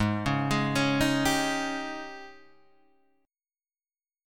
G# Major 7th Suspended 4th